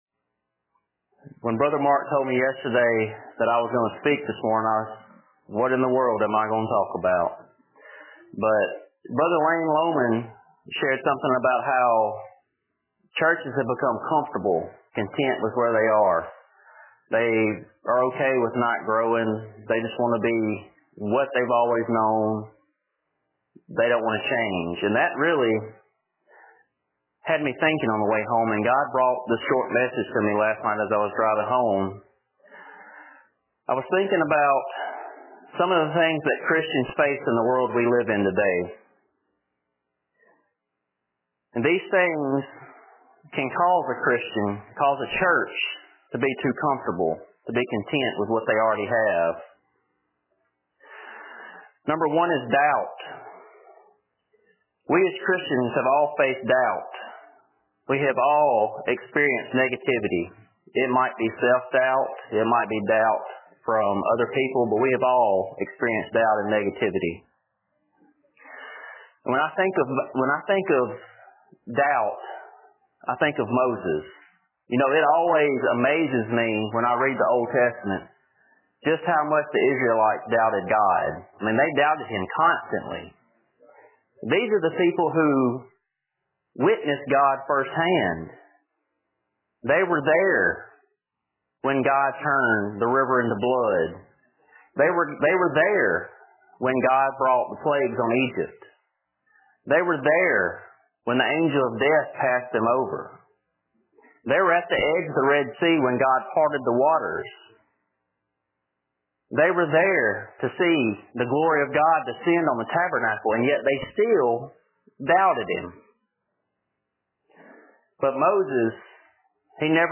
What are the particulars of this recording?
2018 Conference Devotion – Thurs Morning